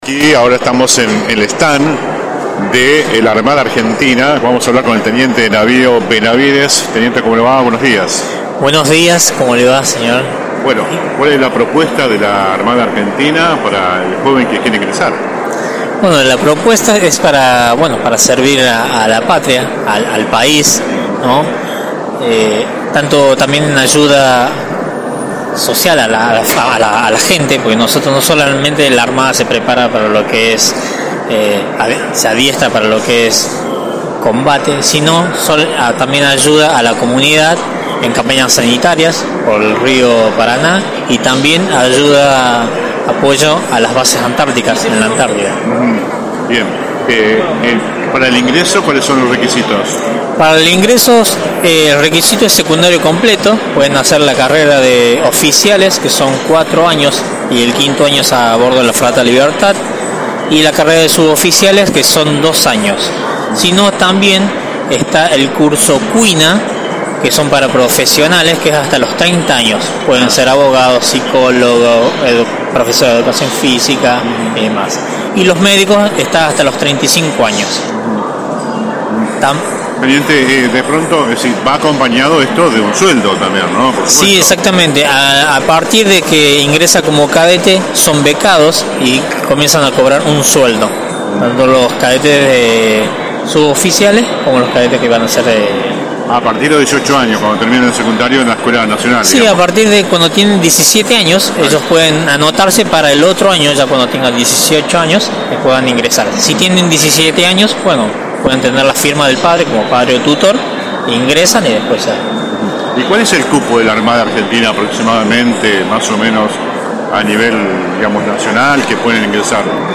(galería de imágenes) La 91.5 continúa visitando este sábado la muestra del campo más importante a nivel nacional.